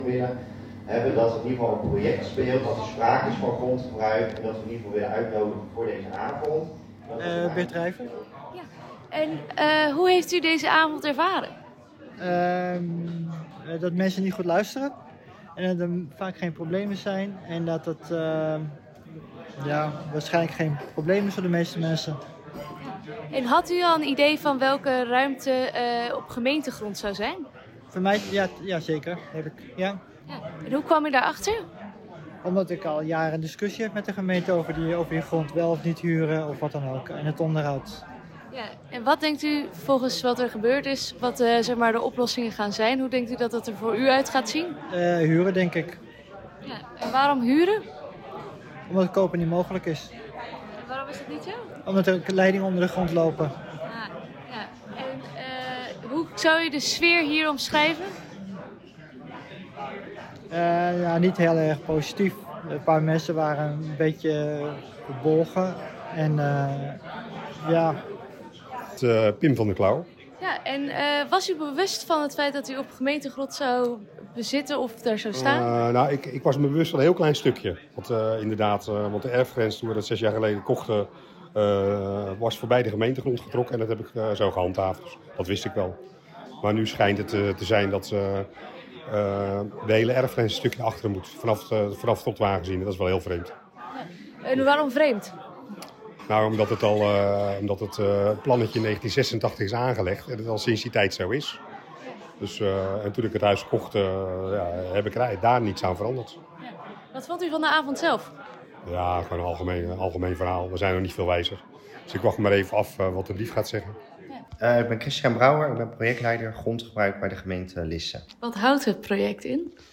Ondanks het tropische weer op dinsdag kwamen zo’n 50 inwoners naar de informatieavond.
Radioreportage